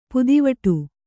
♪ pudivaṭu